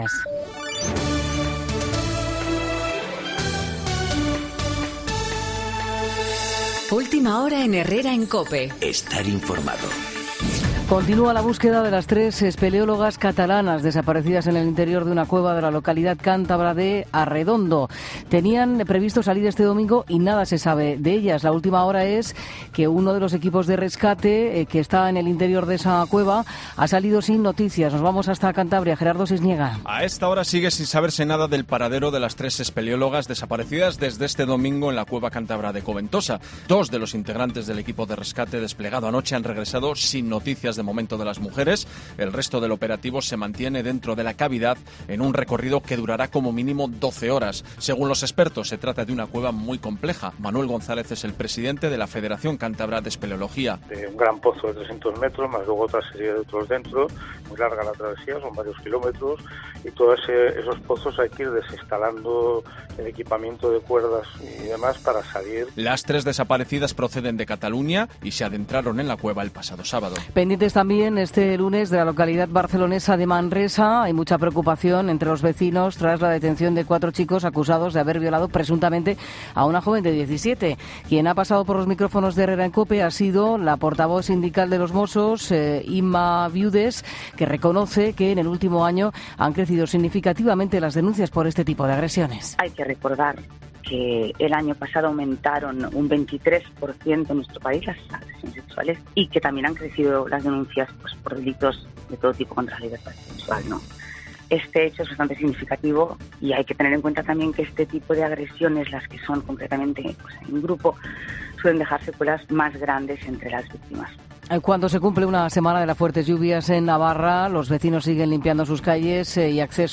Boletín de noticias COPE del lunes 15 de julio a las 11.00 horas